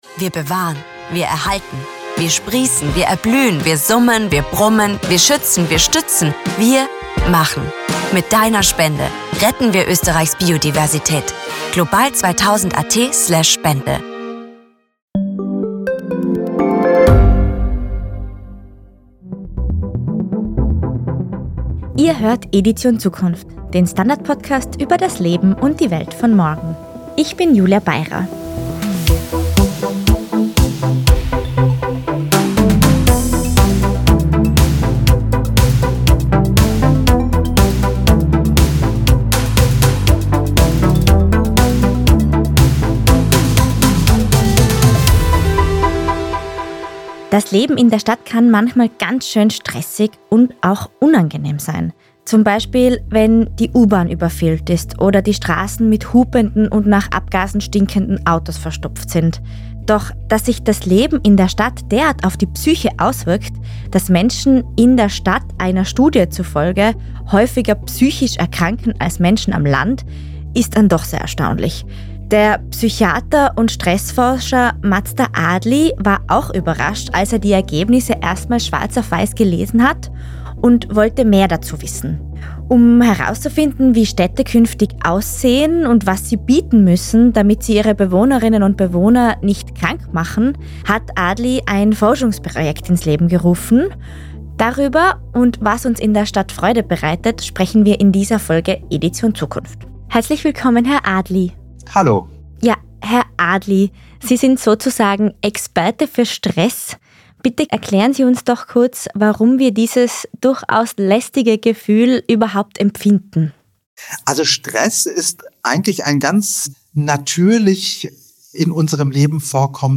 Edition Zukunft ist der STANDARD-Podcast über das Leben und die Welt von morgen. Die Redaktion spricht mit Experten über Entwicklungen, die unseren Alltag verändern - von künstlicher Intelligenz und Robotern bis hin zu Migration und Klimawandel.